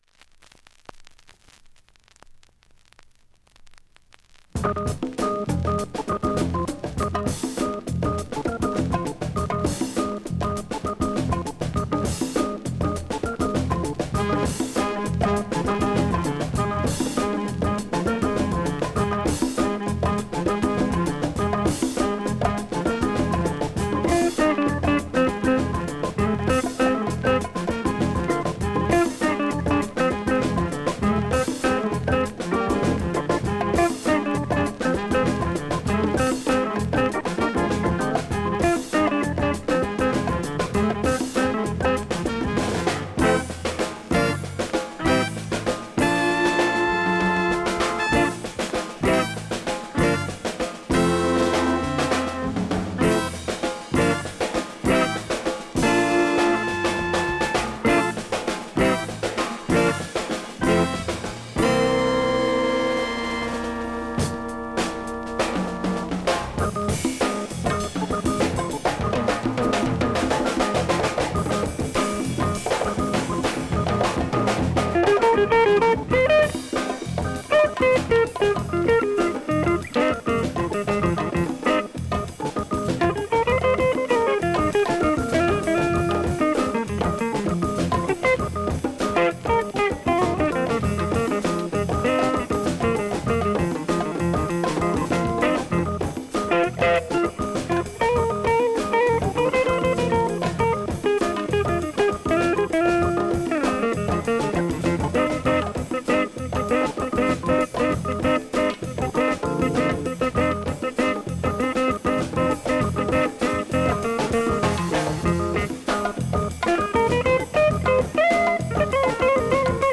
◆盤質両面/ほぼVG+普通に聴けます◆